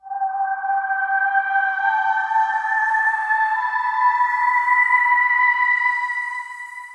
synth05.wav